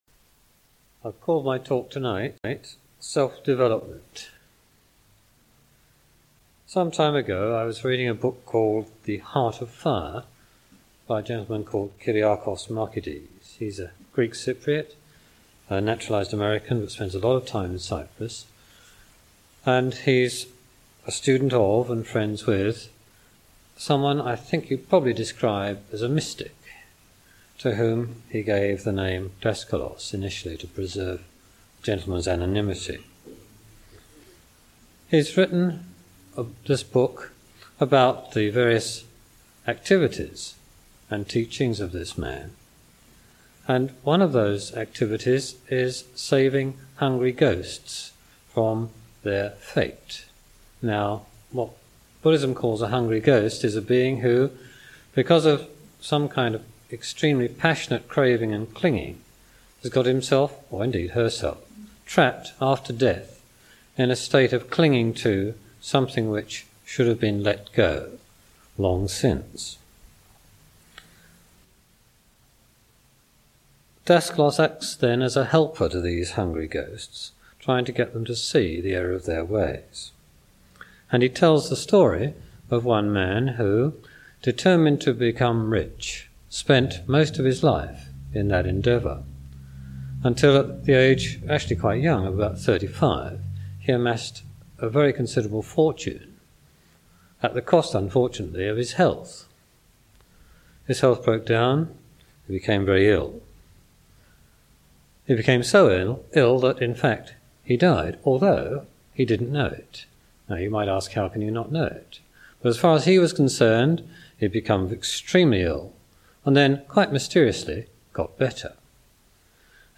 This talk was originally given in April 1991.